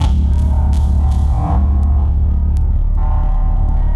Abstract Rhythm 39.wav